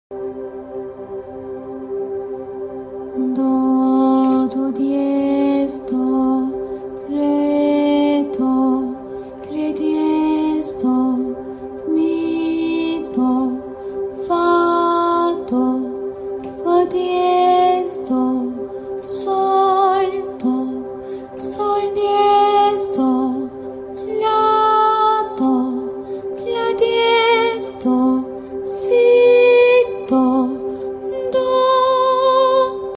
Ses ve nefes egzersizi yapmadan sound effects free download
Chromatic/Kromatik Do Majör dizisinde seslere hakimiyetimizi sağlamak için bu provayı beraber yapalım. İster nota isimlerini benimle beraber söyleyin ister sesli harflerden birini seçip provayı çeşitlendirin.